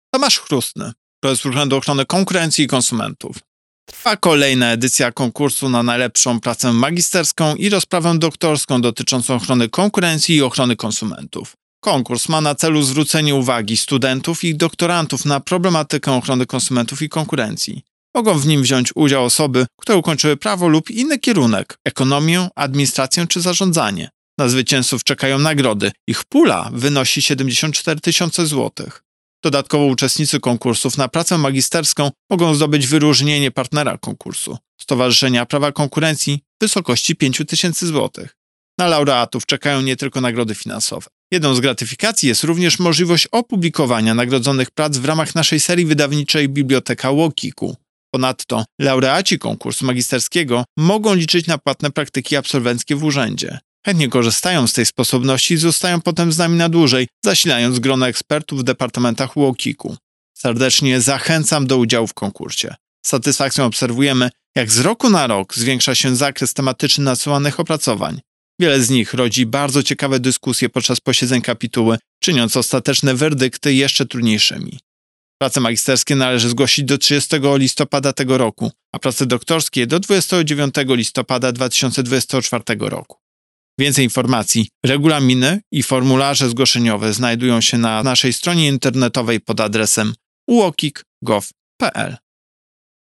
Pobierz wypowiedź Prezesa UOKiK Tomasza Chróstnego " Serdecznie zachęcam do udziału w konkursie.